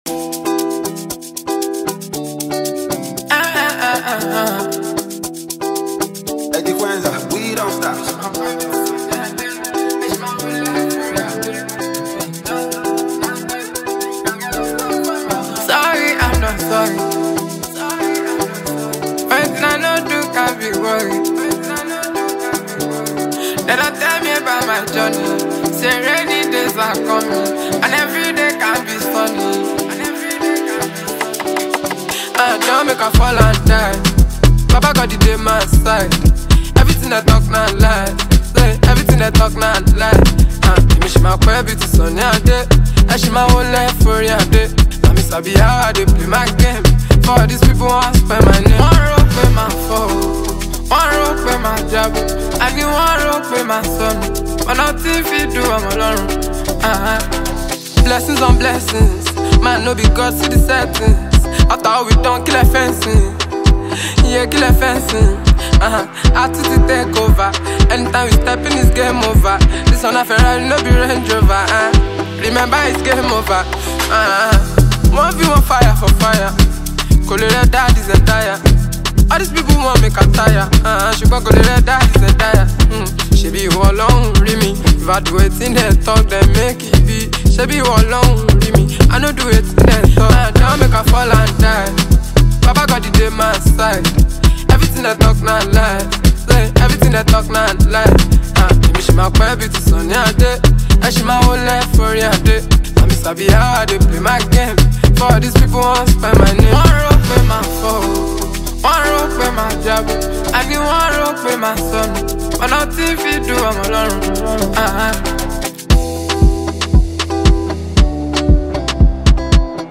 gbedu